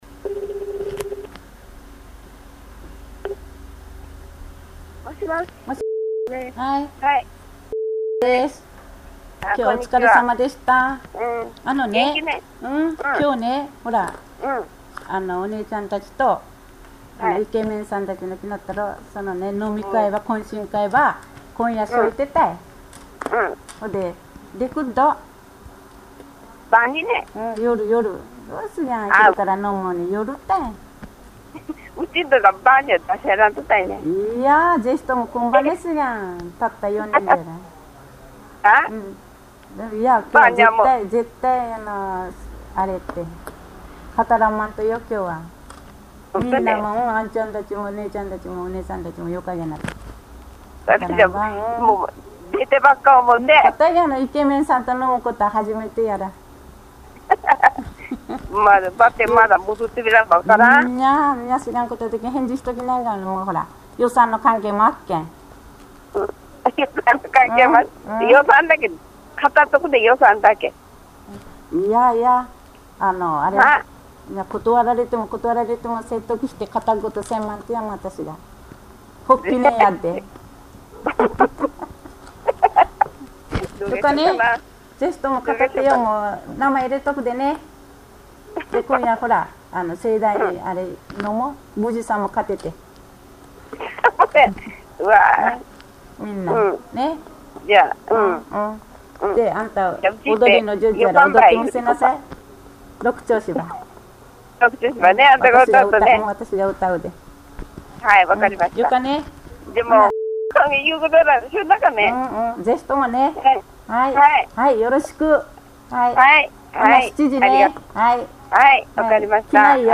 ペア入れ替え式ロールプレイ会話
人吉高年層女性ペア１